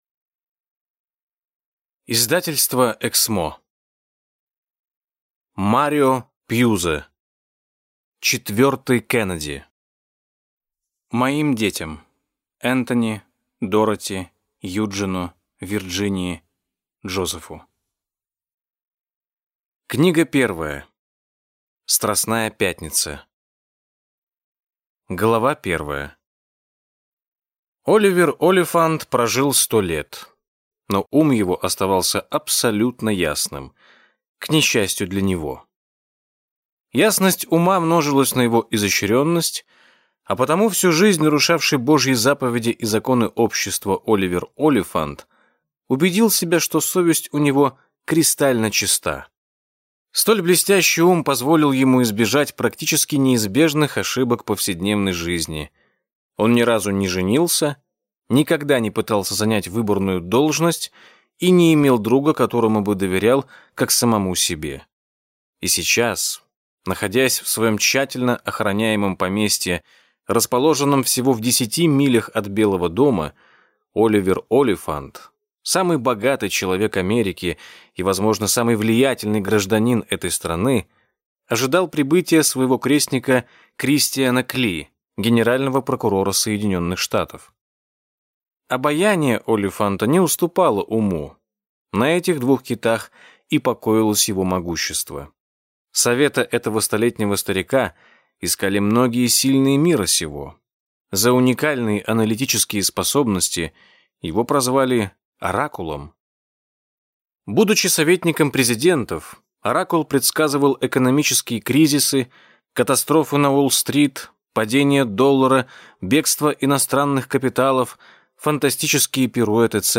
Аудиокнига Четвертый Кеннеди | Библиотека аудиокниг